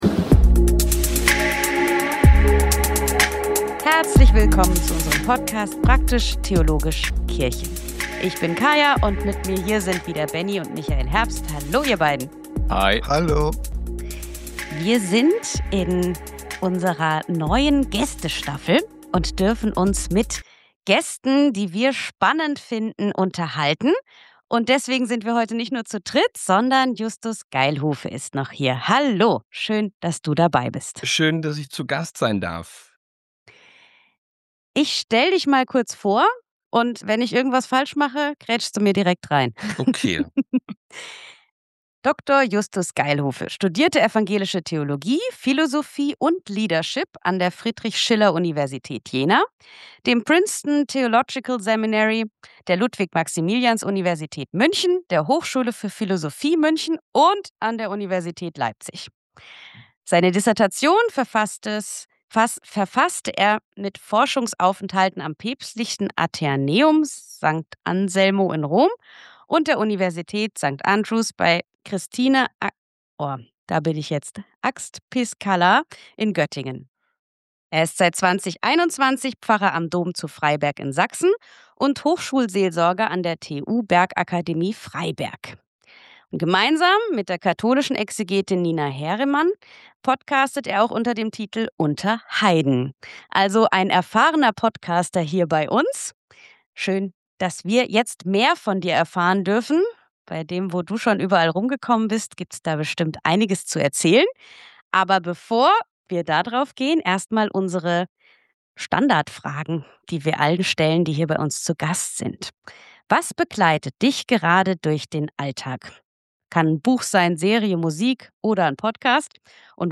Wir befinden uns in unserer zweiten Staffel, in der wir mit faszinierenden Gästen ins Gespräch kommen! Wir reden mit ihnen über das, was sie begeistert und wo sie Chancen und neue Perspektiven für die Kirche sehen.